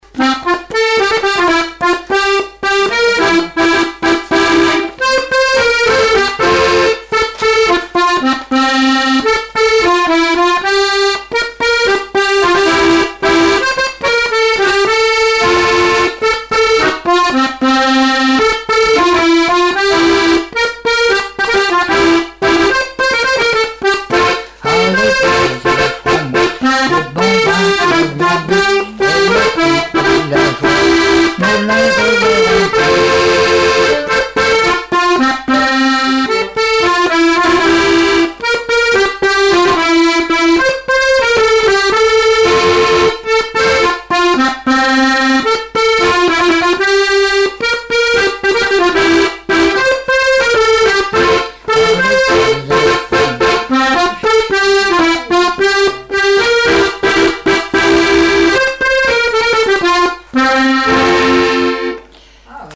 danse : marche
Répertoire du musicien sur accordéon chromatique
Pièce musicale inédite